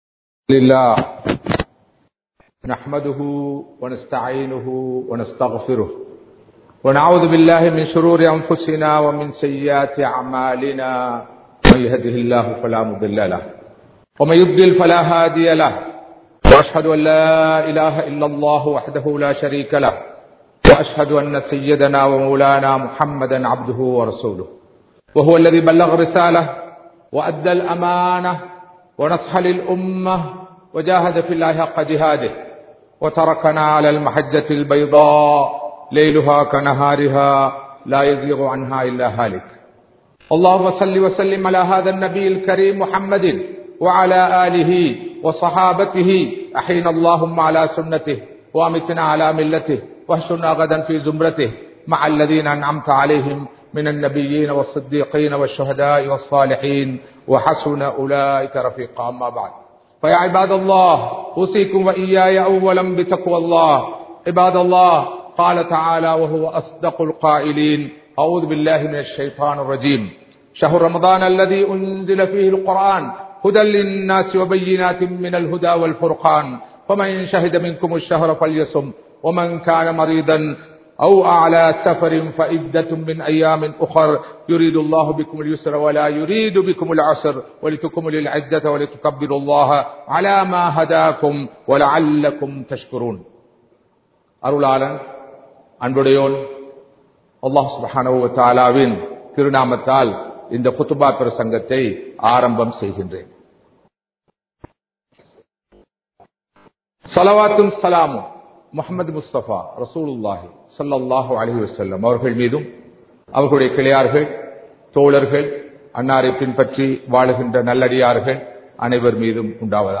முன்மாதிரியான முஸ்லிமின் வீடு | Audio Bayans | All Ceylon Muslim Youth Community | Addalaichenai